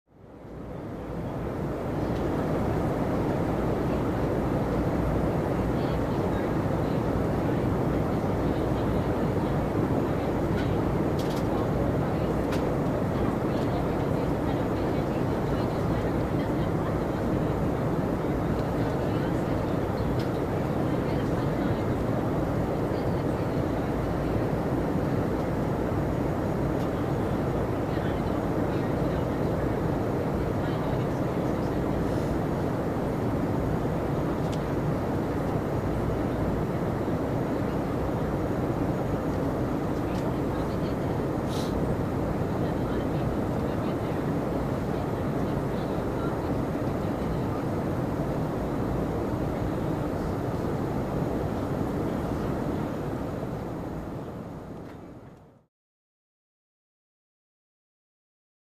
Passenger Cabin Ambience, Airy With Light Passenger Walla